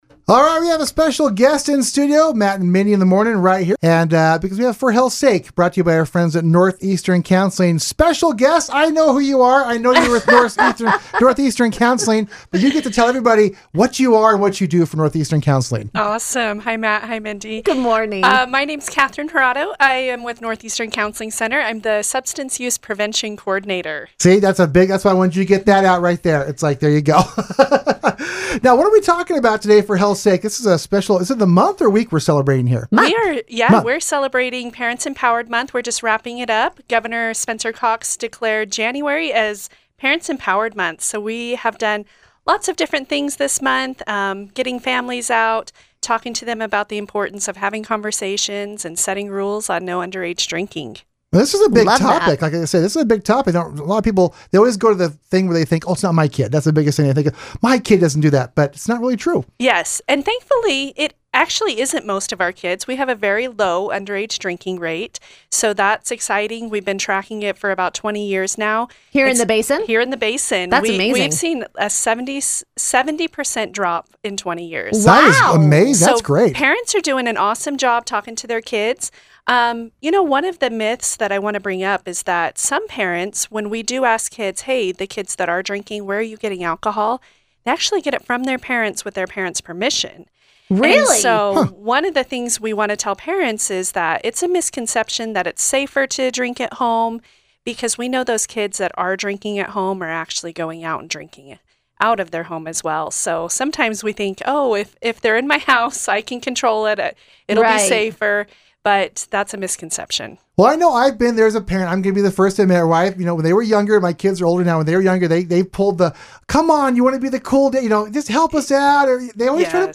Weekly Radio Spots